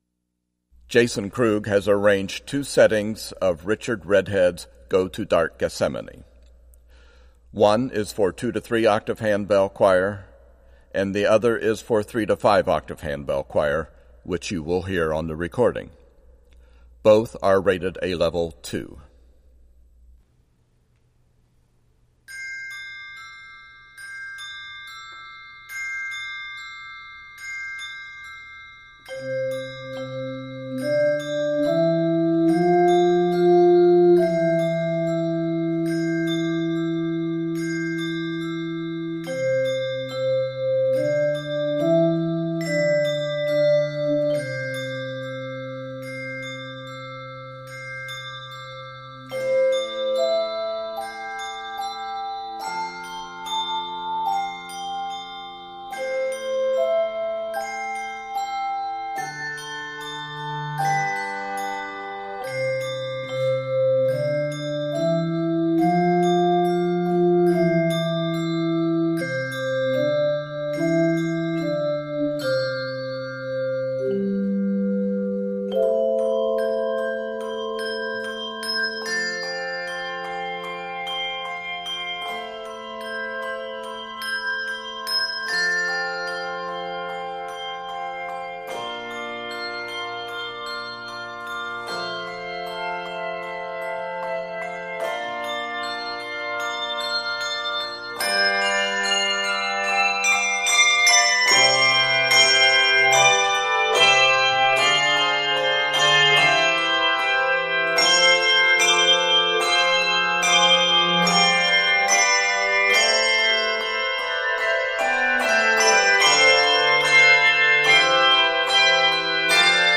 Key of C Major. 51 measures.